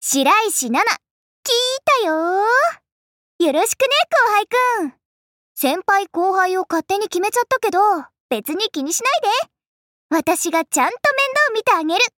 白石奈奈自我介绍语音.mp3